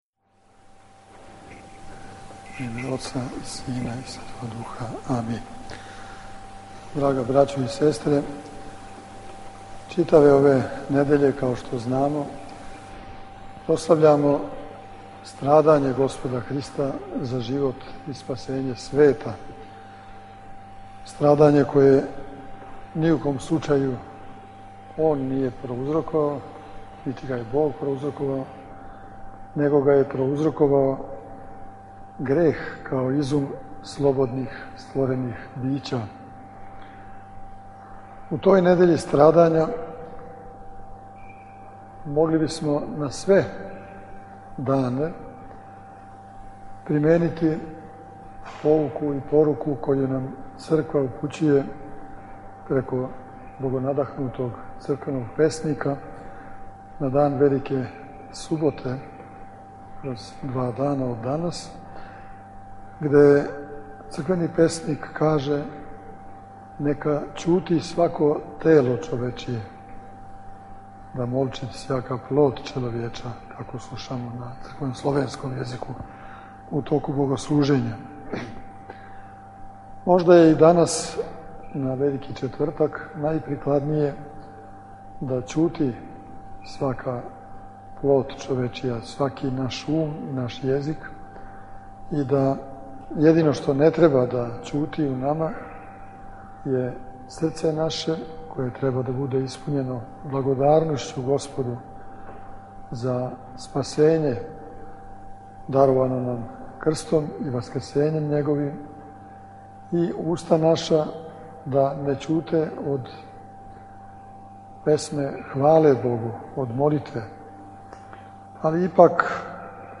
На Велики четвртак, 21. априла 2011. године, свету архијерејску Литургију у новосадском Саборном храму служио је Његово Преосвештенство Епископ бачки Господин др Иринеј.